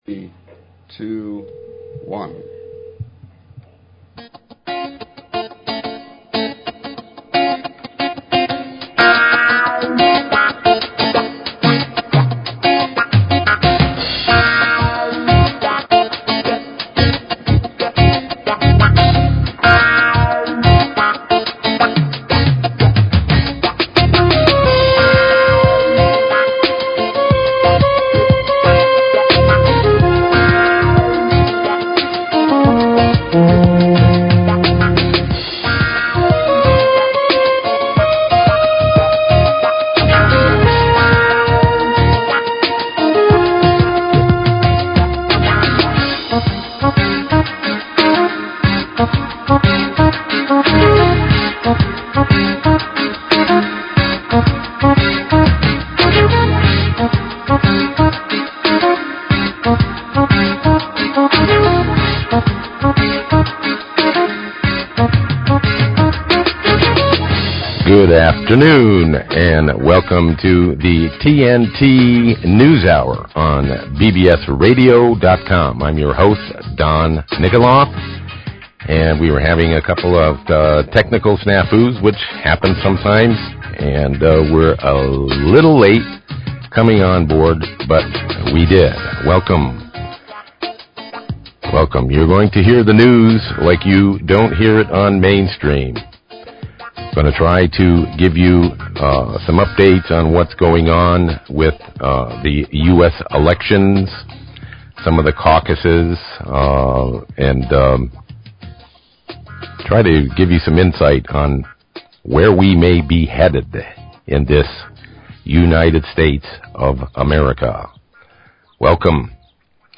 Talk Show Episode, Audio Podcast, The_TNT_Hour and Courtesy of BBS Radio on , show guests , about , categorized as